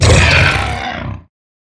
Minecraft Version Minecraft Version snapshot Latest Release | Latest Snapshot snapshot / assets / minecraft / sounds / mob / hoglin / death2.ogg Compare With Compare With Latest Release | Latest Snapshot